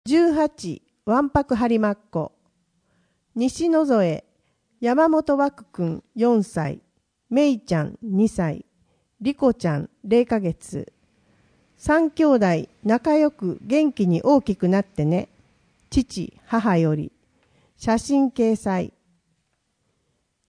声の「広報はりま」2月号
声の「広報はりま」はボランティアグループ「のぎく」のご協力により作成されています。